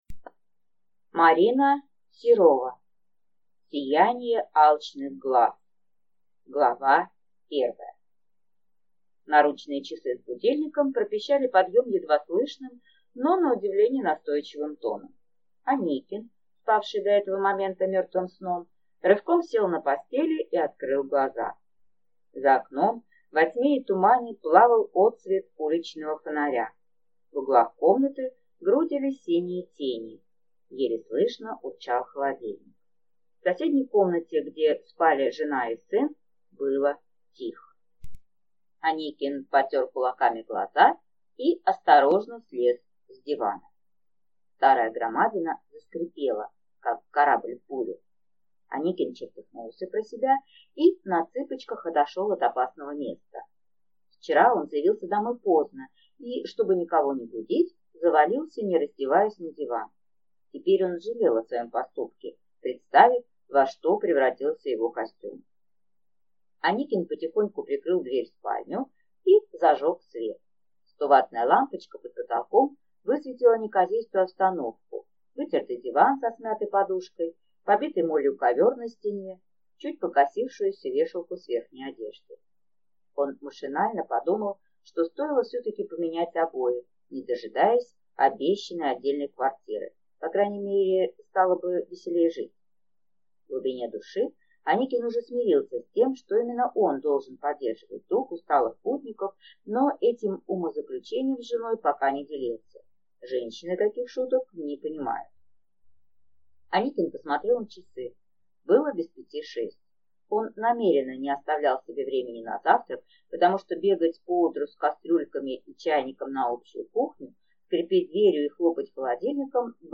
Аудиокнига Сияние алчных глаз | Библиотека аудиокниг